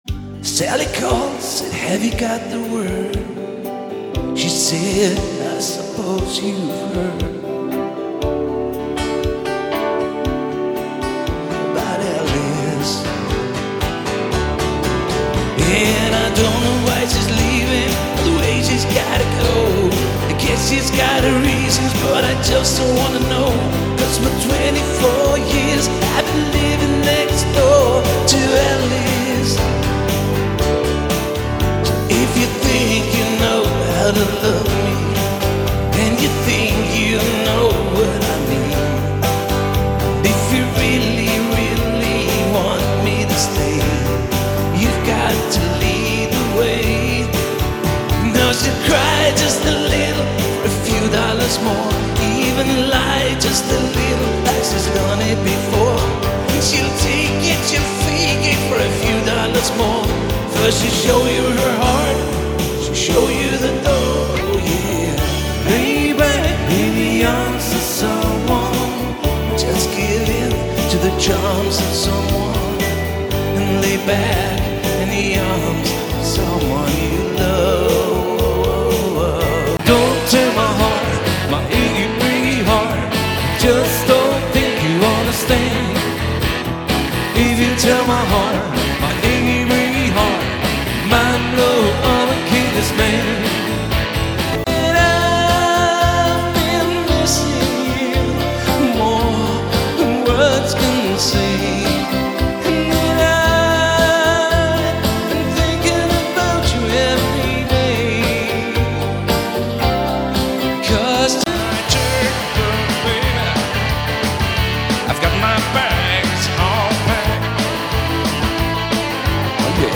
er repertoiret baseret på god dansemusik og fællessang
(Vokal, rytmeguitar og mundharpe), er bandets ”Benjamin”.
(Piano & keyboards) er bandet suverænt højeste medlem.